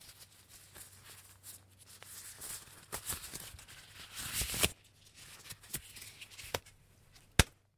На этой странице собраны звуки капельницы в разных вариантах: от монотонного стекания жидкости до фонового шума больничной палаты.
Звук протирания места укола спиртовой ваткой